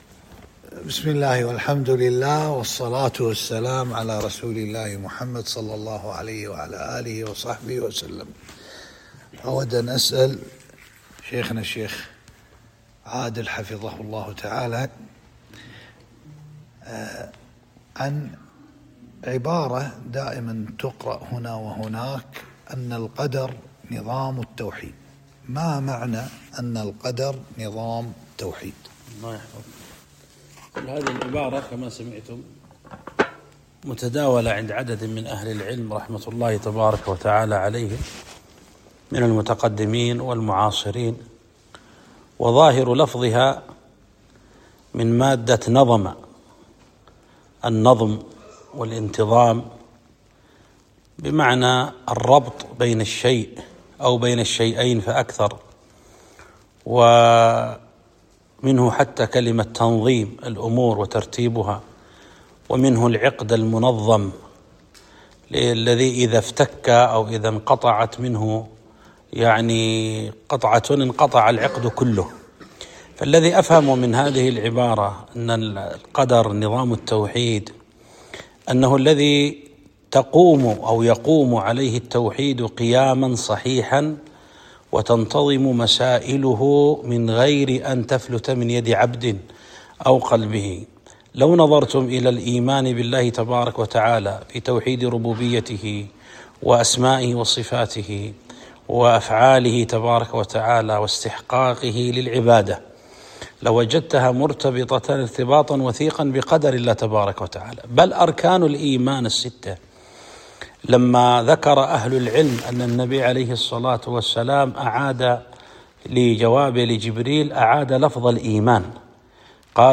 لقاء مفتوح